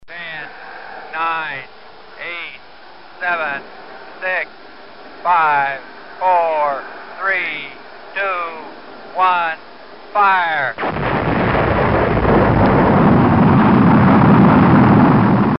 Голос обратного отсчета перед стартом космической ракеты